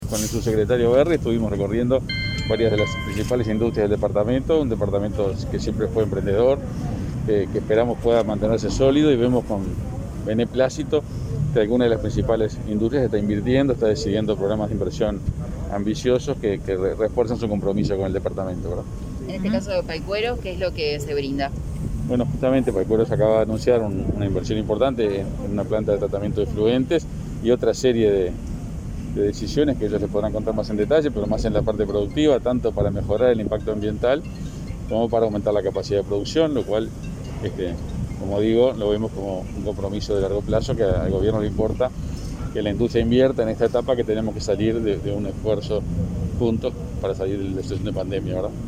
Palabras del ministro de Industria, Omar Paganini, luego de la visita a la fábrica de Paycueros, en Paysandú